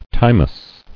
[time·ous]